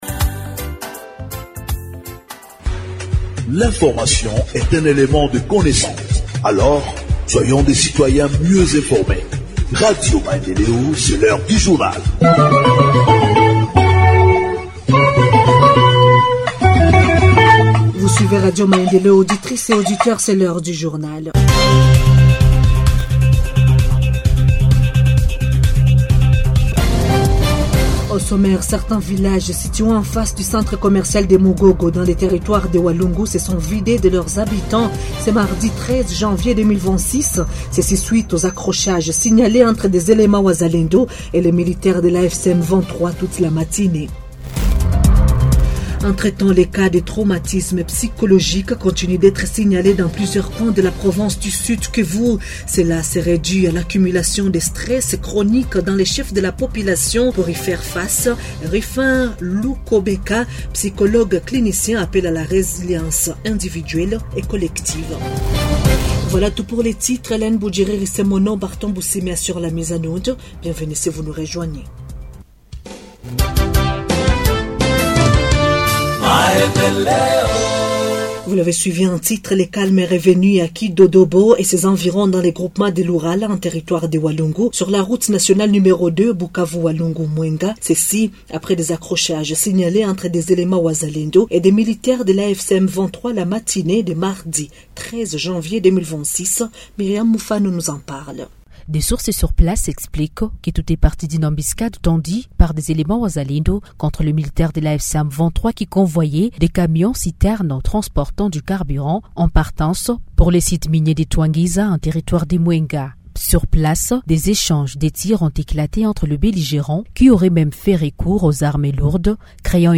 Journal en Français du 14 Janvier 2026 – Radio Maendeleo